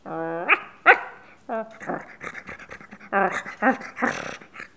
Les sons ont été découpés en morceaux exploitables. 2017-04-10 17:58:57 +02:00 822 KiB Raw History
bruit-animal_17.wav